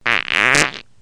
peido-cagado.mp3